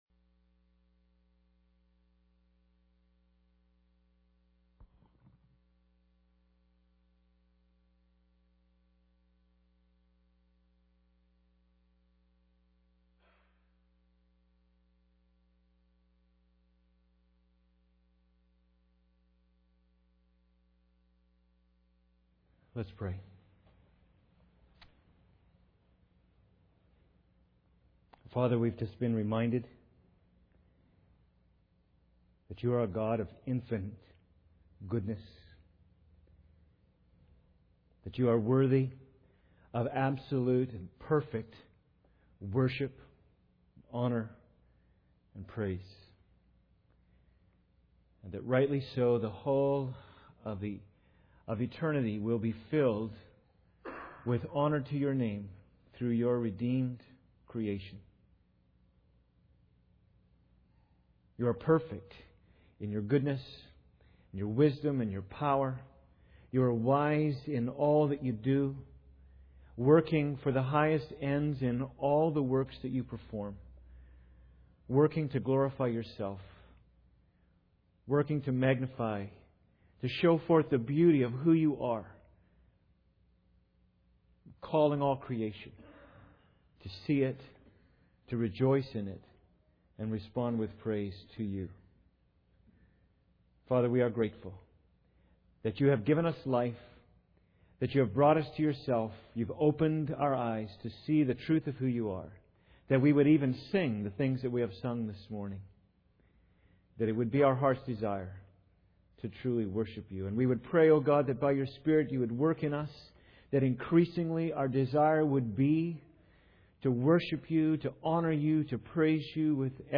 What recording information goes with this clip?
Ephesians 3:14-19 Service Type: Sunday Service Introduction I. Pursue the empowering of the Spirit